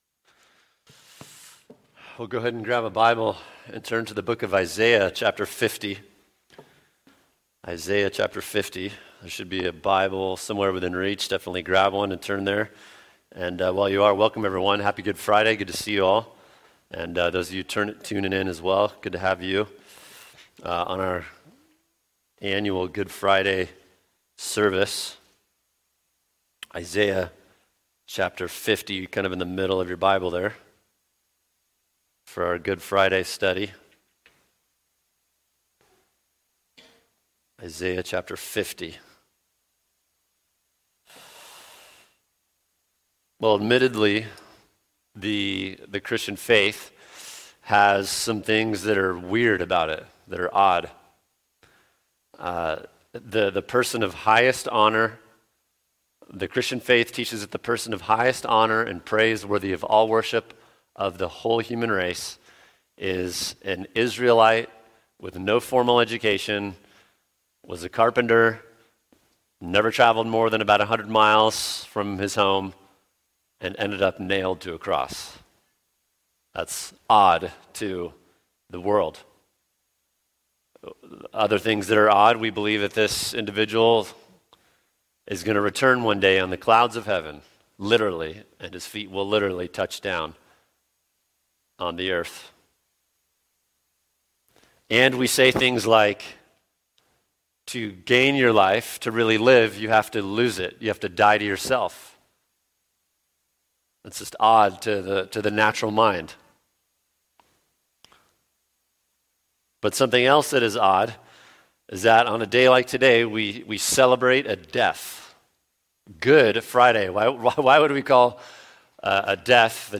[sermon] Isaiah 50:4-9 Our Glorious Servant-King (Good Friday) | Cornerstone Church - Jackson Hole